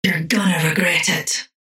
Paradox voice line - You're gonna regret it.